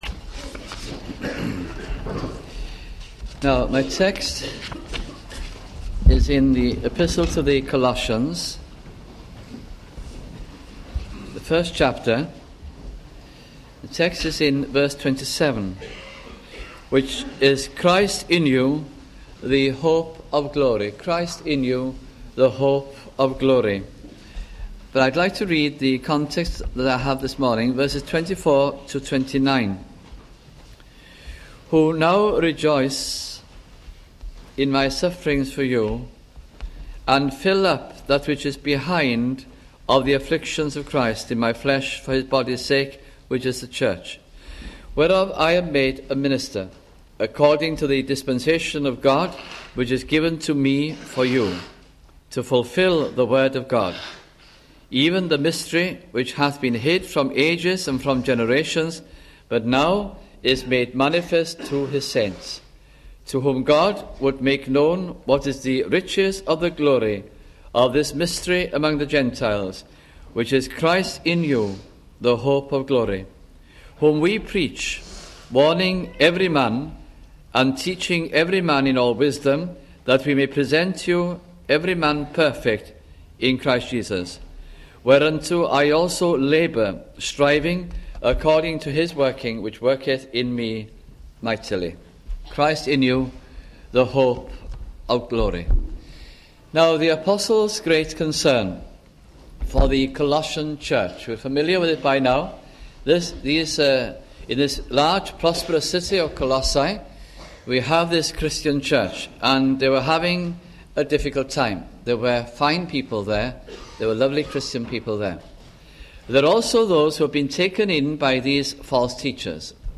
» Colossians Series 1988 - 1989 » sunday morning messages